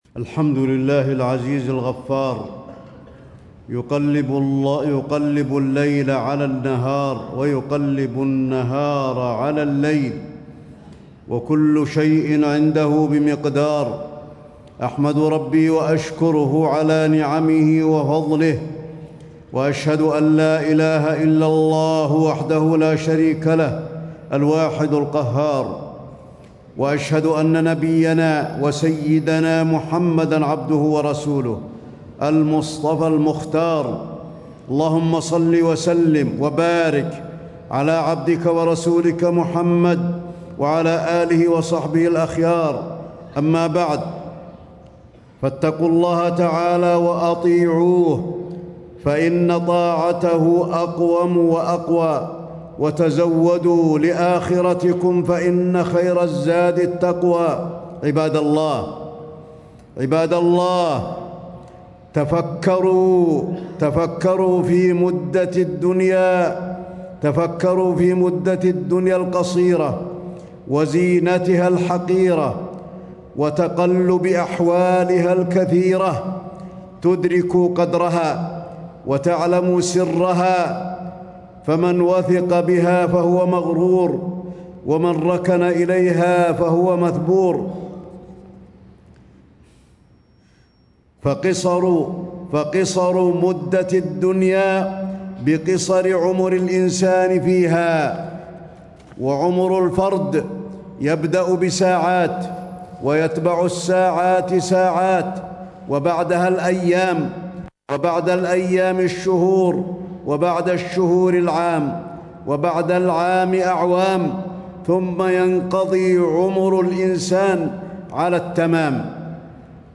تاريخ النشر ٢٤ ربيع الثاني ١٤٣٦ هـ المكان: المسجد النبوي الشيخ: فضيلة الشيخ د. علي بن عبدالرحمن الحذيفي فضيلة الشيخ د. علي بن عبدالرحمن الحذيفي الحذر من الاغترار بالدنيا